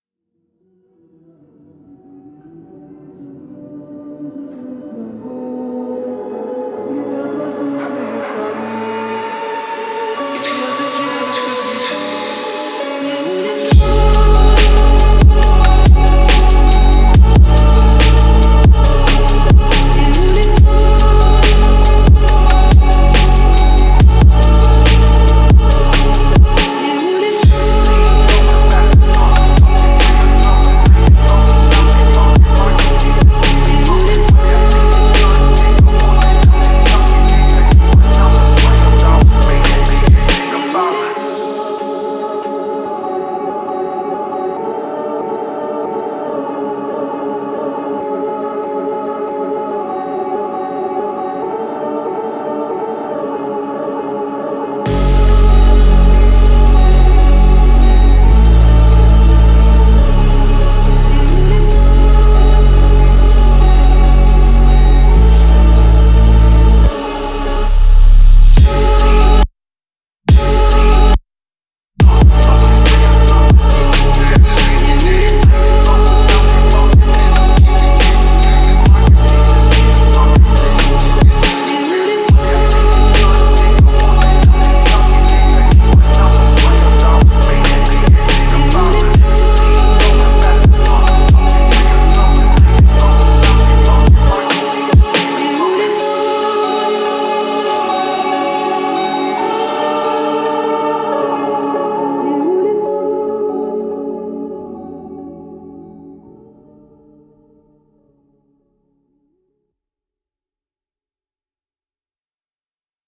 Категория: Фонк музыка